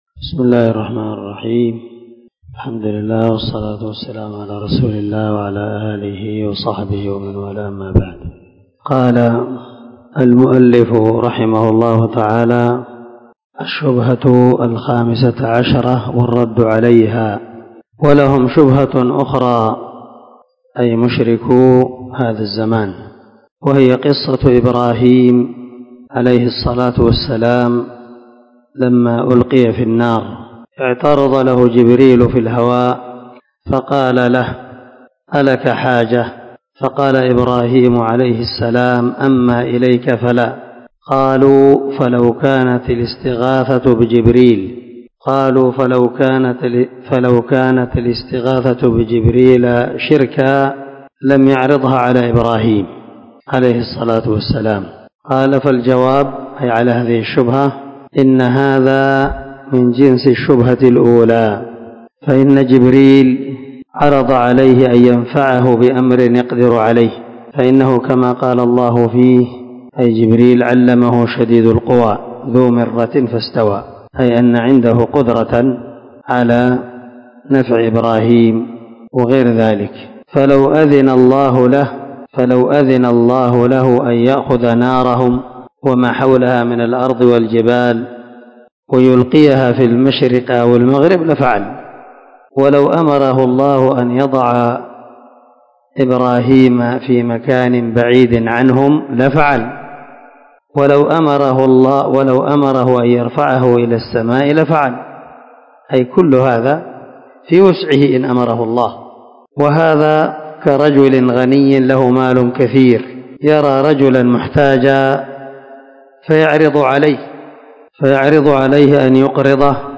شرح كشف الشبهات 0022 الدرس 21 من شرح كتاب كشف الشبهات